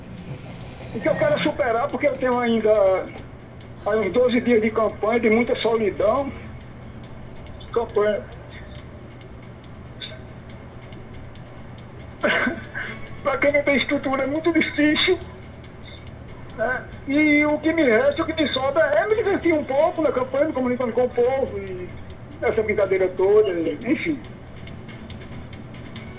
Candidato à ALPB se emociona ao desabafar sobre ameaça: “Para quem não tem estrutura é difícil”; Ouça!